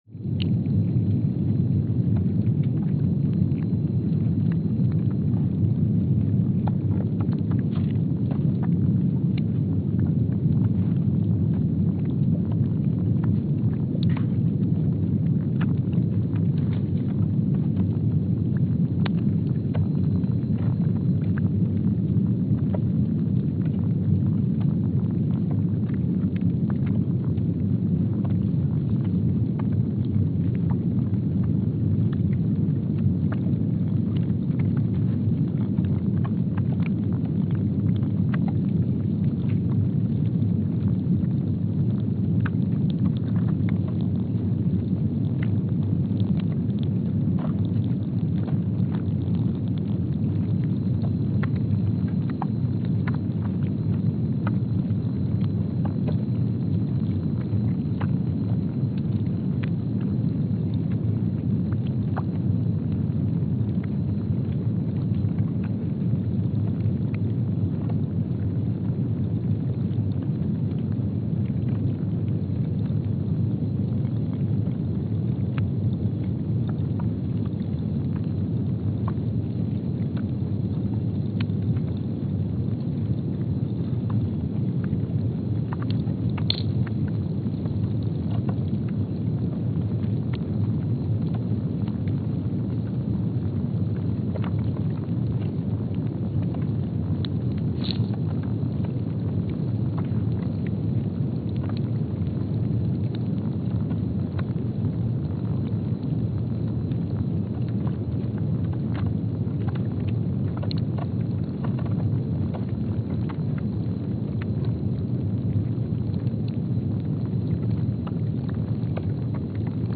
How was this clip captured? Palmer Station, Antarctica (seismic) archived on February 19, 2025 Station : PMSA (network: IRIS/USGS) at Palmer Station, Antarctica Speedup : ×500 (transposed up about 9 octaves) Loop duration (audio) : 05:45 (stereo) Gain correction : 25dB